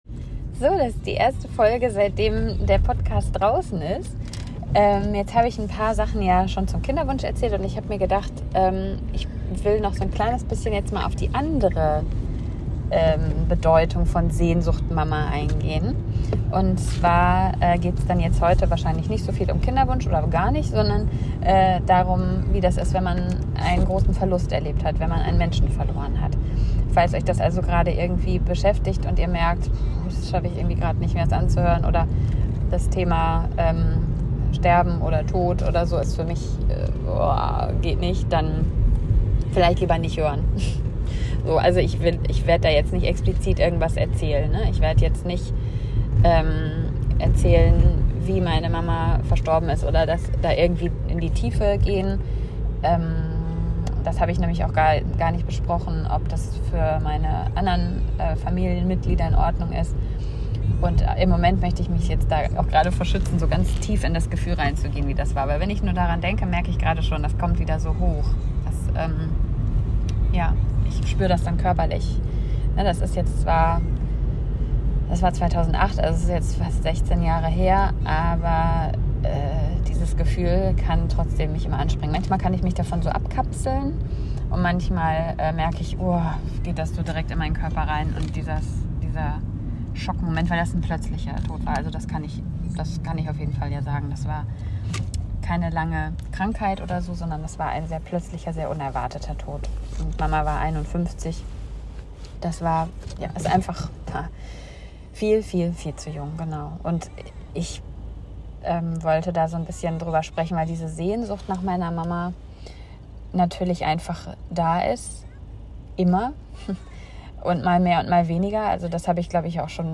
Es kommen sogar Tränen - an einer Stelle an der ich es selbst gar nicht erwartet habe.